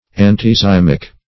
Antizymic \An`ti*zym"ic\, a. Preventing fermentation.